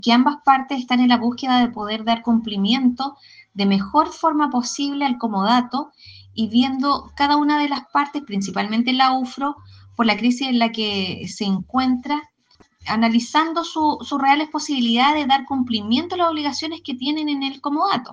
La concejala del Partido Republicano, Marina Matus, recordó que la UFRO intenta salir de una crisis financiera y que por lo mismo debe revisar su posibilidad real de cumplir con las obligaciones del comodato en Pucón.